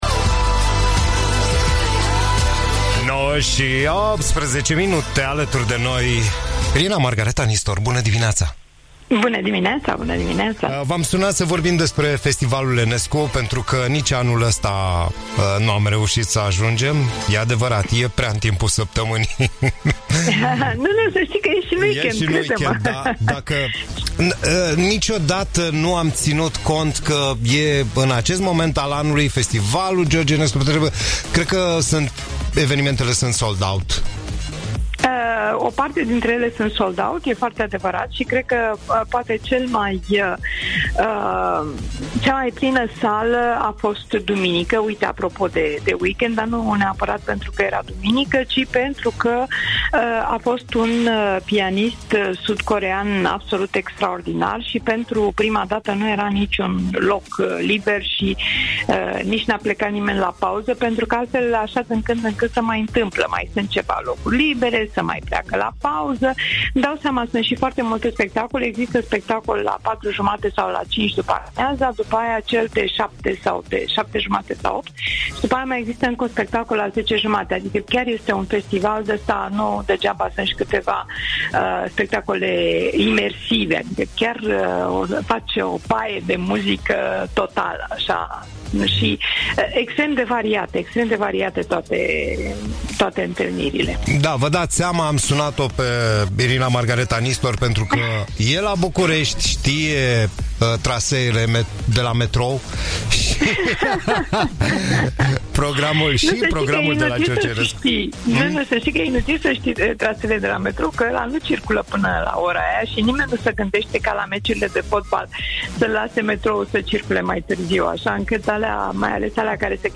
Interviu-Irina-Margareta-Nistor.mp3